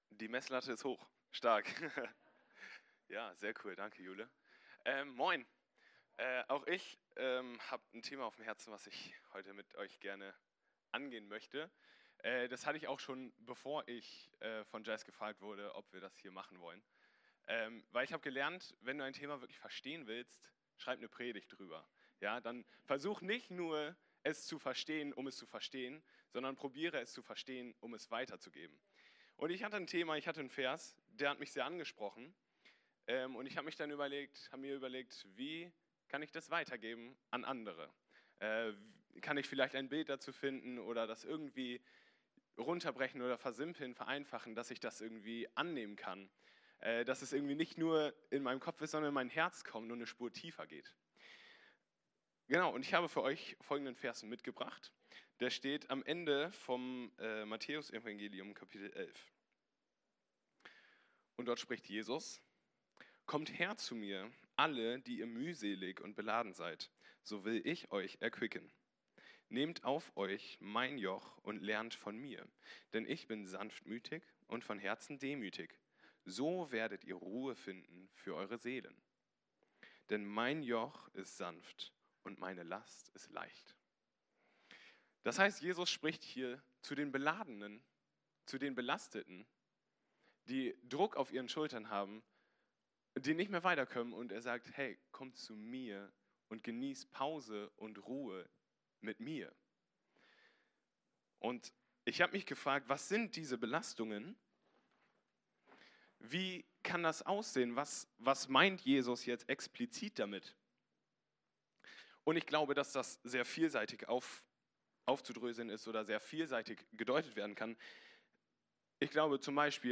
Eine predigt aus der predigtreihe "Goldstückepredigt."